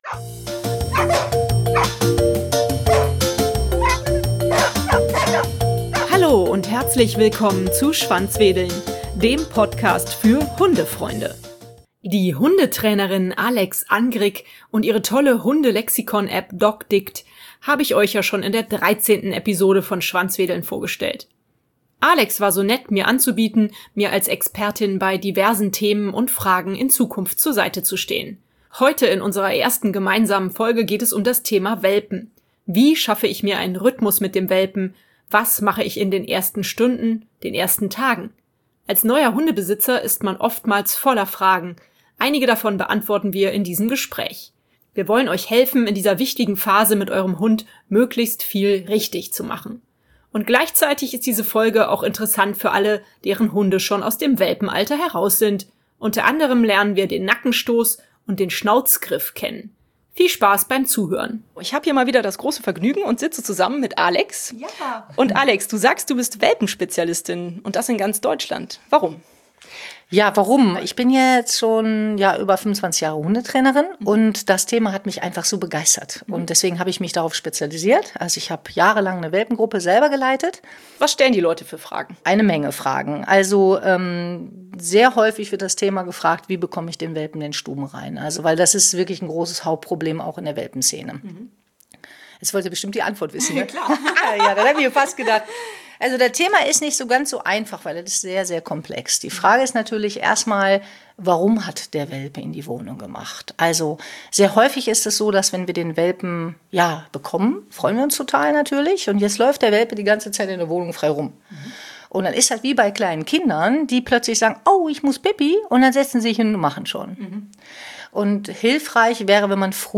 Wie schaffe ich mir einen Rhythmus mit dem Welpen, was mache ich in den ersten Stunden, den ersten Tagen? Als neuer Hundebesitzer ist man oftmals voller Fragen, einige davon beantworten wir in diesem Gespräch.